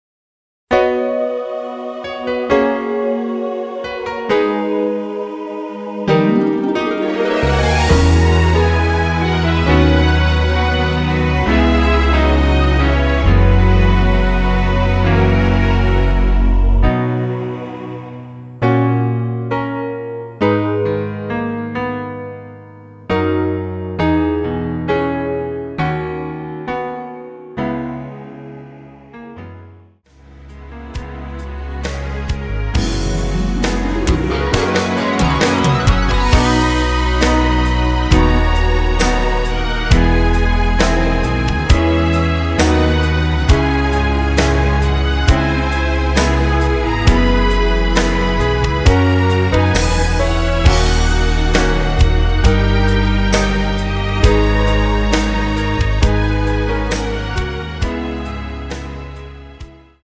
앞부분30초, 뒷부분30초씩 편집해서 올려 드리고 있습니다.
중간에 음이 끈어지고 다시 나오는 이유는
곡명 옆 (-1)은 반음 내림, (+1)은 반음 올림 입니다.